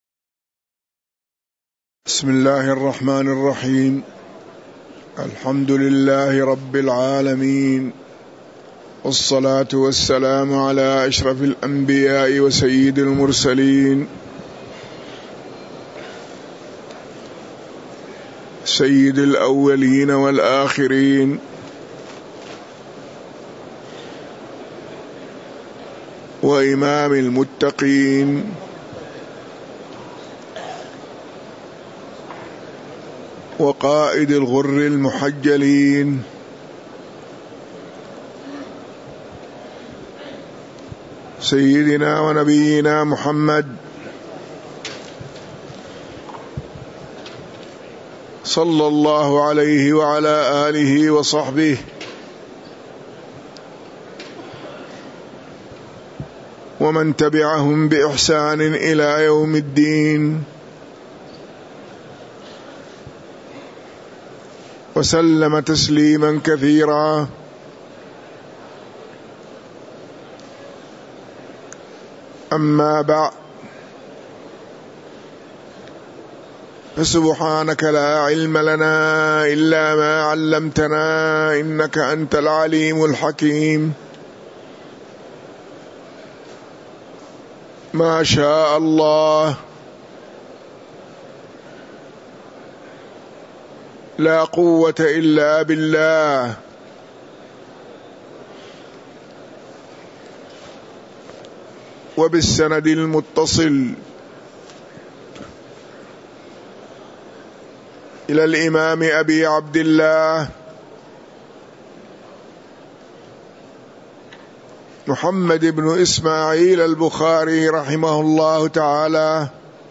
تاريخ النشر ١٨ ذو الحجة ١٤٤٣ هـ المكان: المسجد النبوي الشيخ